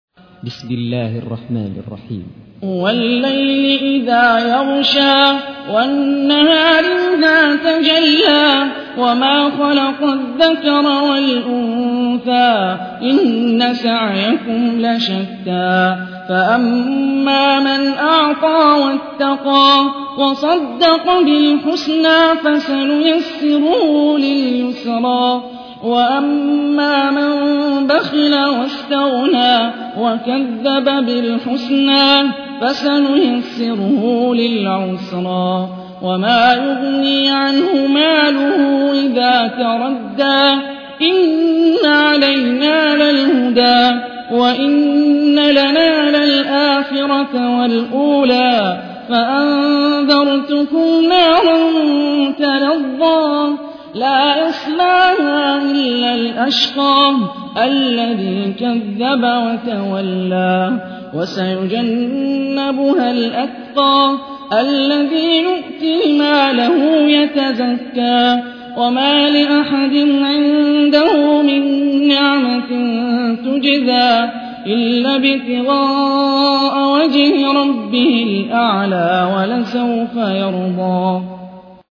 تحميل : 92. سورة الليل / القارئ هاني الرفاعي / القرآن الكريم / موقع يا حسين